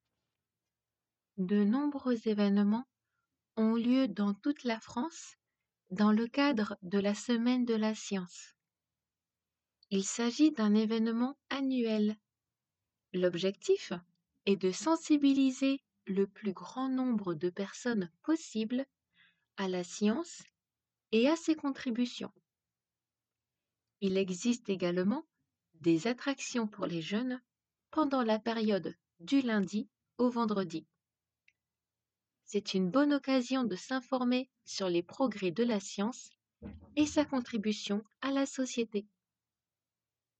デイクテ　－練習用
普通の速さでーー